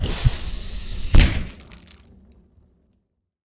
fire-bolt-001-90ft.ogg